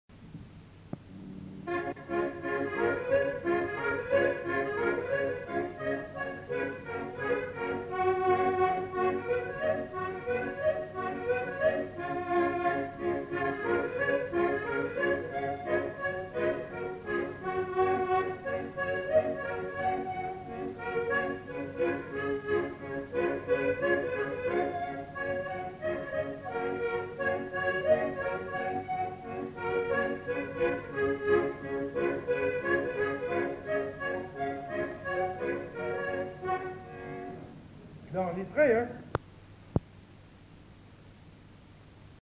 Instrumental. Accordéon diatonique
Lieu : Monclar d'Agenais
Genre : morceau instrumental
Instrument de musique : accordéon diatonique
Notes consultables : Valse ou mazurka ou java ?